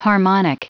Prononciation du mot harmonic en anglais (fichier audio)
Prononciation du mot : harmonic